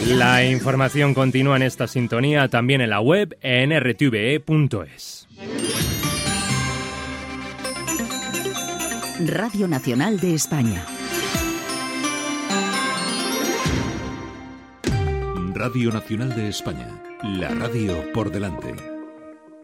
Senyals horaris, hora (19:00) i careta del butlletí. Sortida del butlletí. Indicatiu de l'emissora.
Informatiu